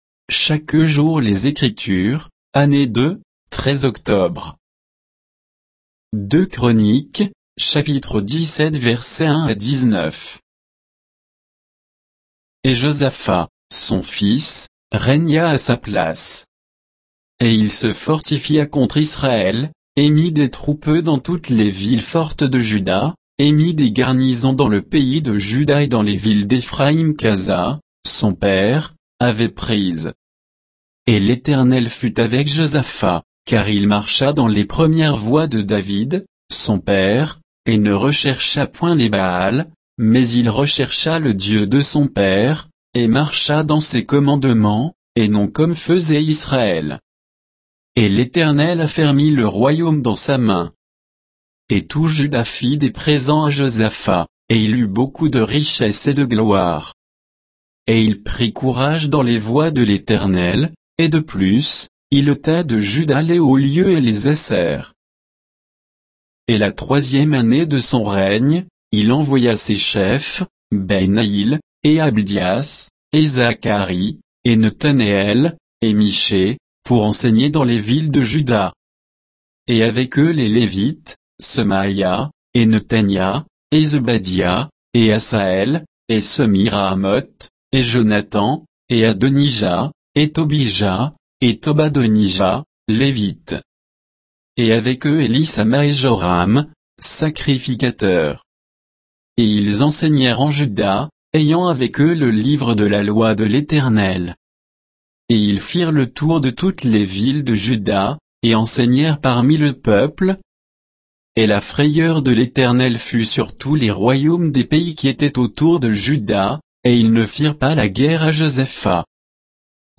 Méditation quoditienne de Chaque jour les Écritures sur 2 Chroniques 17, 1 à 19